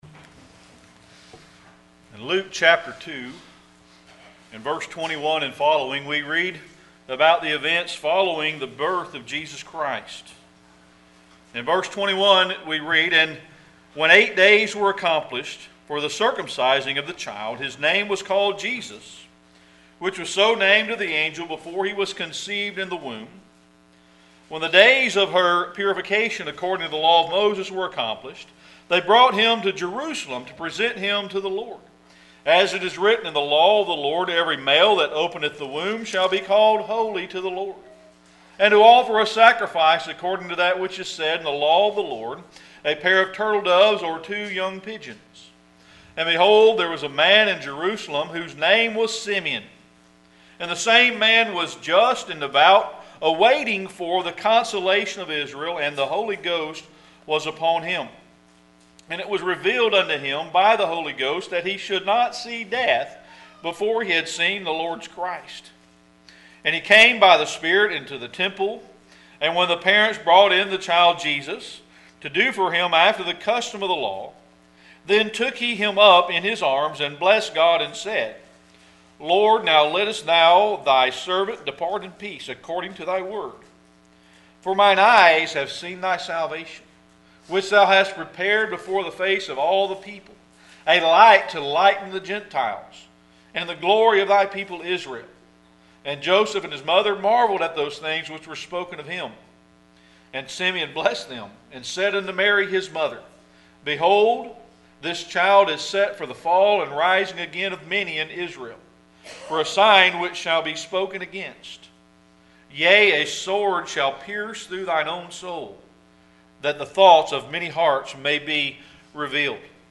Luke 2:21-39 Service Type: Sunday Evening Worship Luke 2:21-39 At the time that the Lord was born